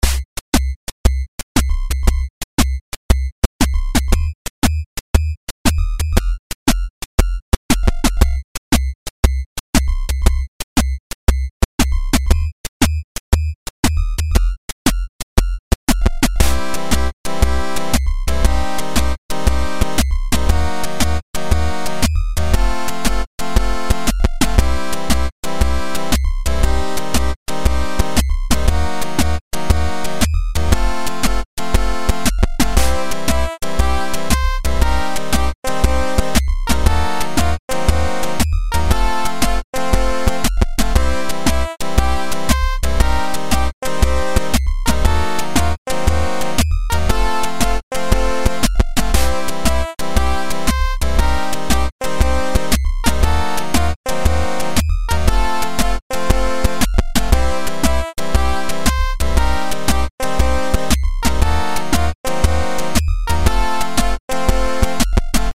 New musical sequence performed with 8-bit sounds.